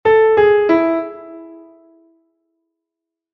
Anacrúsico: o comezo vai antes do acento.
anacrusico.mp3